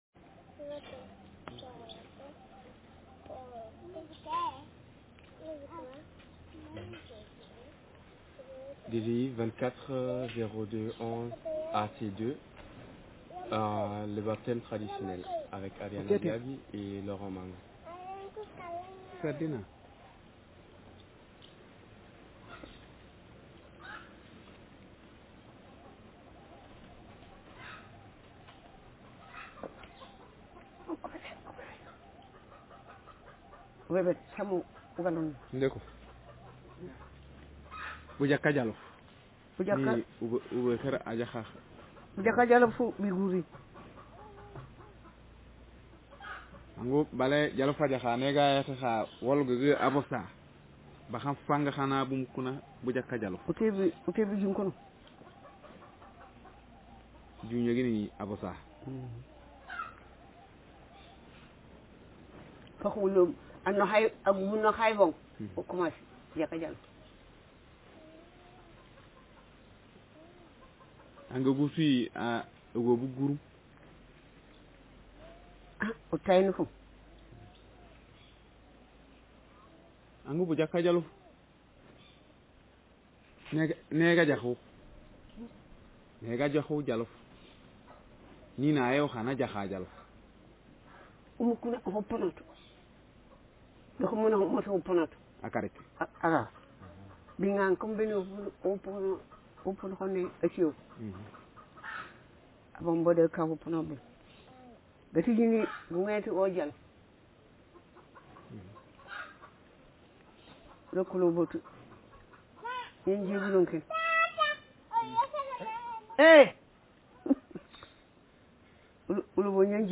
Speaker sexf/m
Text genreconversation